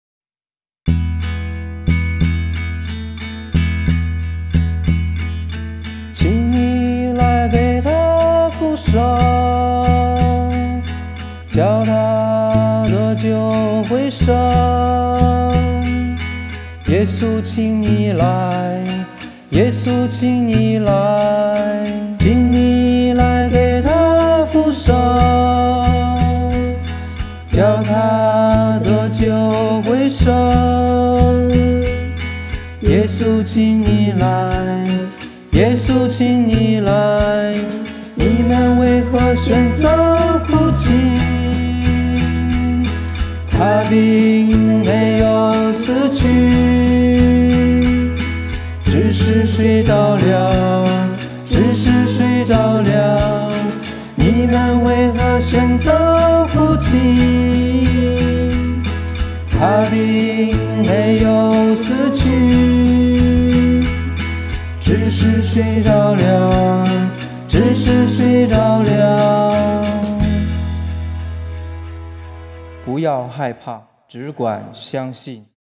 【原创圣歌】|《不要害怕只管相信》香港慈幼会修院顺逆不变参赛作品